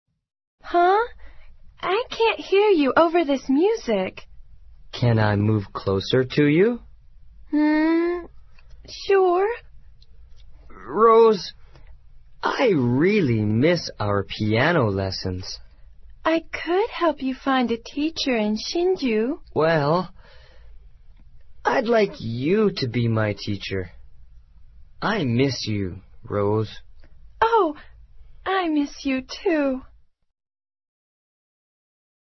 网络社交口语对话第69集：我想念你